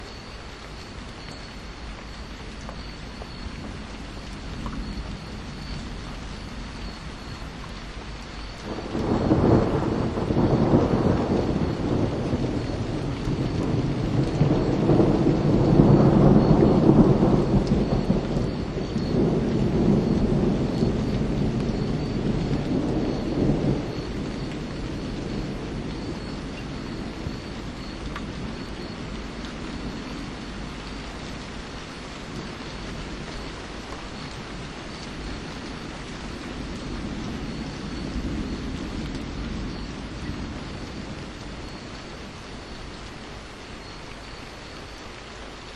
The second microphone has been in use for a while now, an Azden SGM-2X unidirectional ‘shotgun’ mic intended for exactly these kind of uses, fitted with only a ‘dead cat’ wind guard.
Both were also pointed in the same general direction from the same location on the front step, about 30° upwards over the tops of the nearby trees where I could see the flashes and occasional lightning bolt, and by this time it was raining steadily, as can be heard.
Shotgun mic test
That’s a world of difference – the parabolic dish really doesn’t capture the lower registers well at all, though I think the shotgun mic is both optimized for them and has more of a built-in pre-amplifier.
However, I was not using this setting since I wanted to see how low it captured.
ShotgunThunder1.mp3